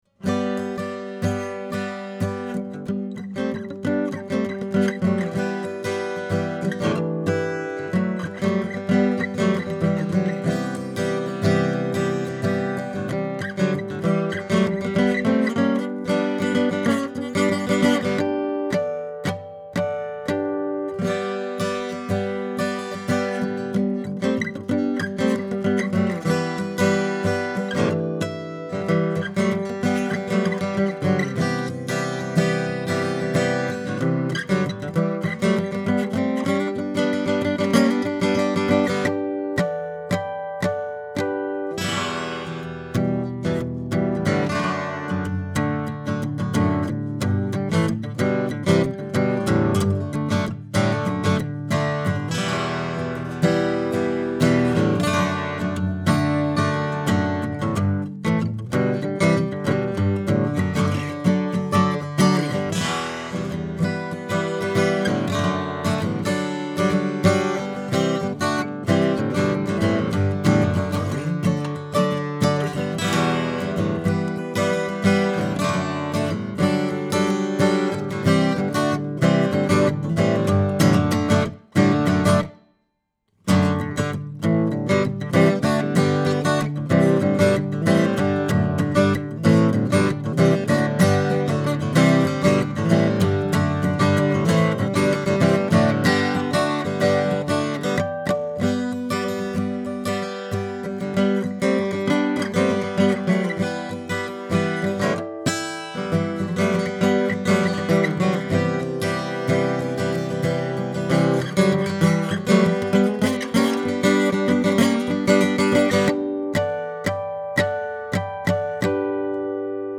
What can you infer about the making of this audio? Here are 33 quick, 1-take MP3s using this UM70 and M70 in a large room going into a Audient Black mic pre, into a Sony PCM D1 flash recorder, with MP3s made from Logic. These tracks are just straight signal with no additional EQ, compresson or effects: M70 CAPSULE TESTS